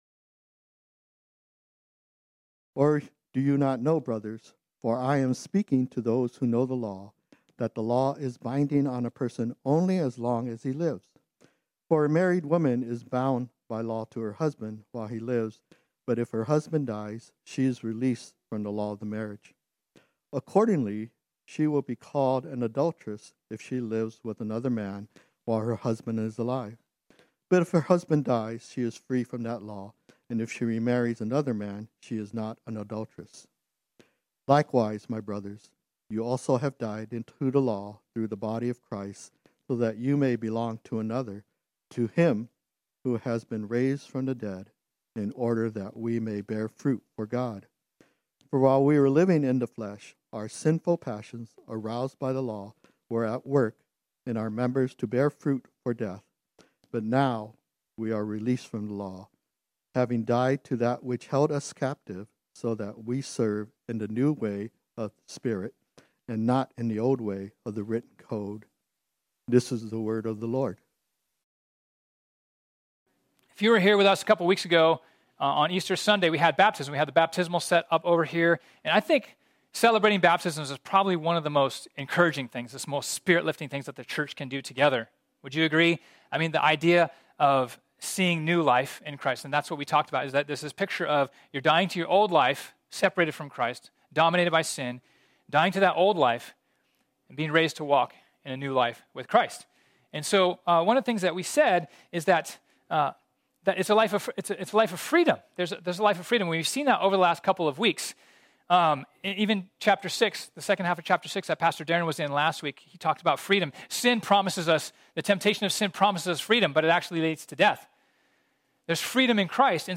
This sermon was originally preached on Sunday, April 18, 2021.